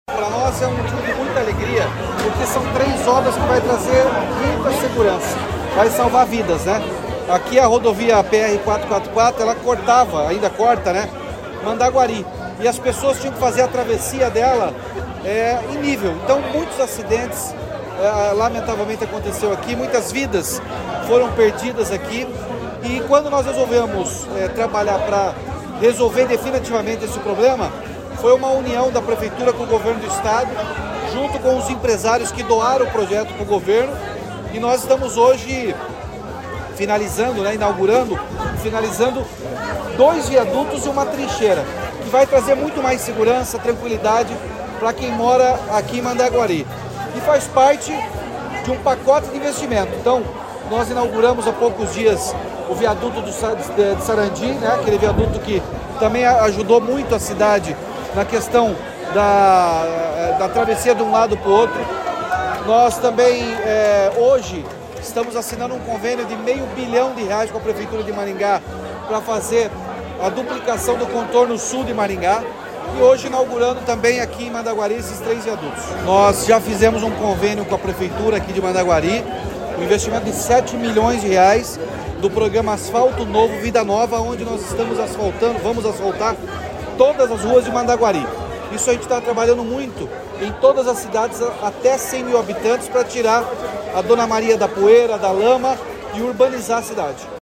Sonora do governador Ratinho Junior sobre a inauguração de viaduto na PR-444 em Mandaguari